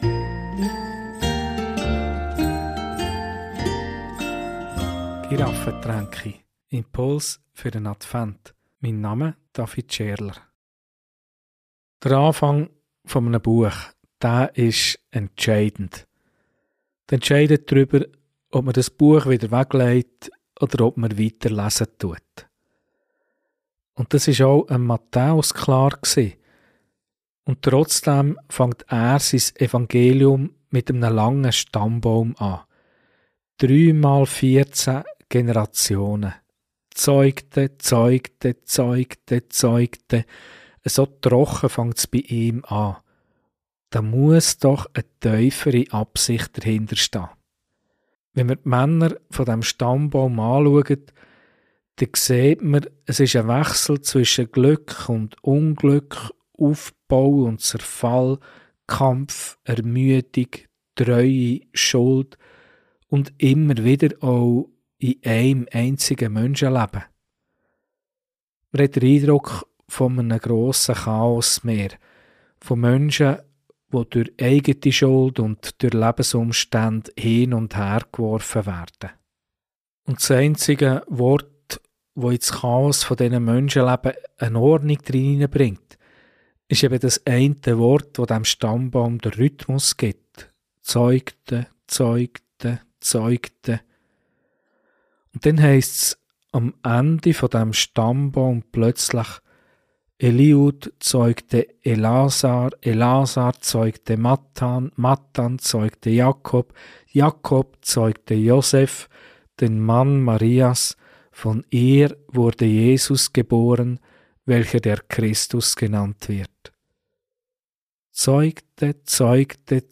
Diese Predigt zeigt: Genau hier beginnt Matthäus seine